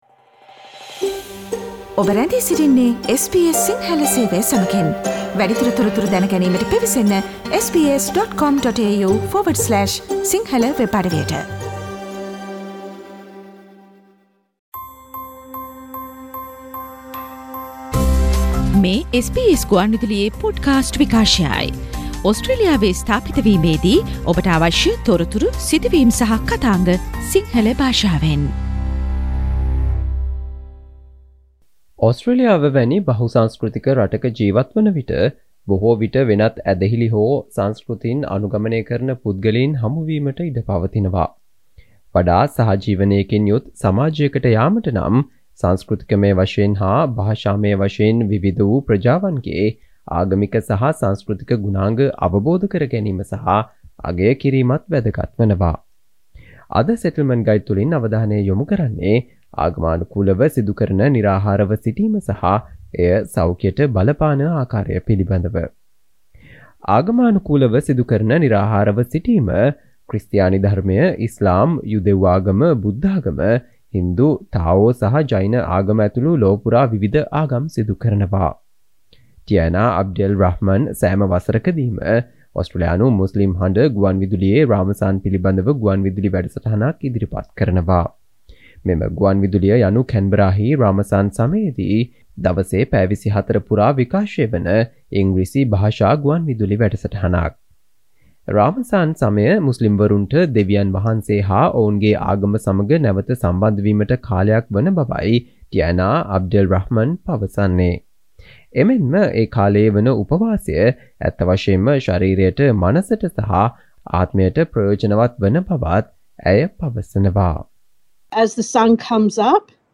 අද මෙම Settlement Guide ගුවන්විදුලි විශේෂාංගයෙන් අපි ඔබ වෙත ගෙන ආ තොරතුරු, කියවා දැන ගැනීමට හැකි වන පරිදි වෙබ් ලිපියක් ආකාරයටත් අපගේ වෙබ් අඩවියේ පලකොට තිබෙනවා.